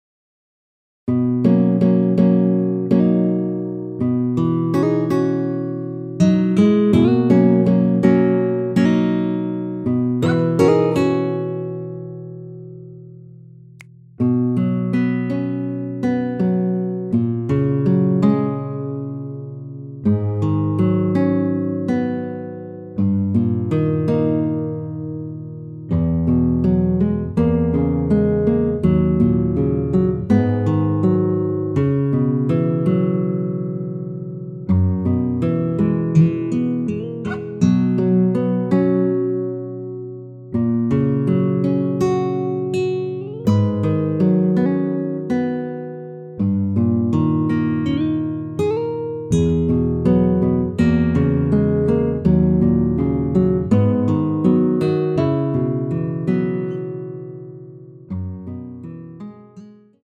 원키에서(-6)내린 MR입니다.
앞부분30초, 뒷부분30초씩 편집해서 올려 드리고 있습니다.